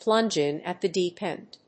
アクセントplúnge ín at the déep énd